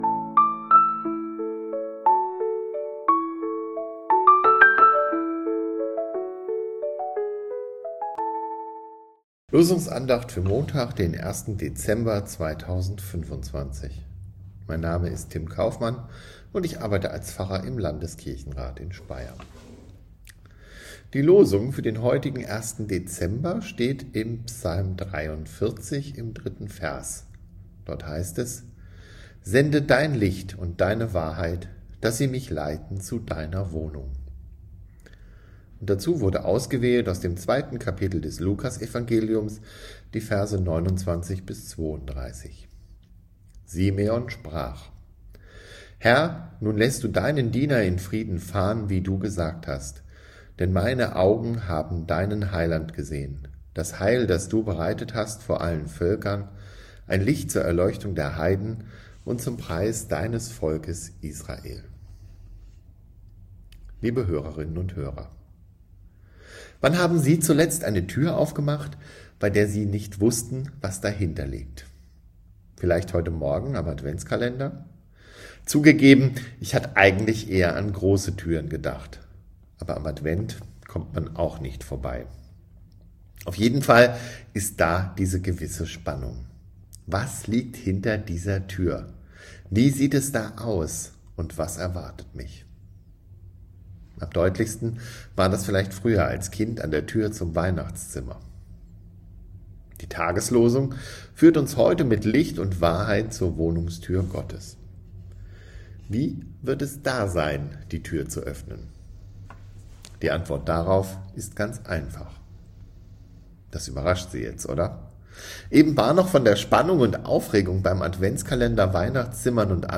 Losungsandacht für Montag, 01.12.2025